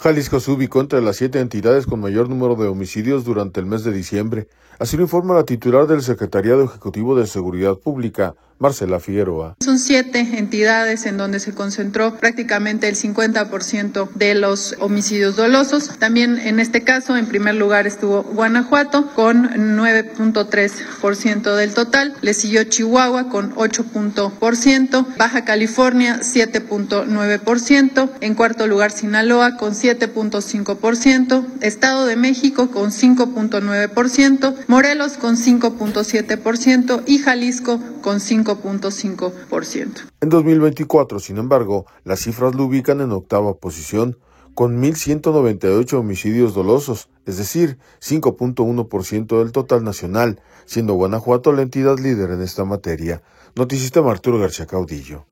Jalisco se ubicó entre las siete entidades con mayor número de homicidios durante el mes de diciembre, así lo informa la titular del Secretariado Ejecutivo de Seguridad Pública, Marcela Figueroa.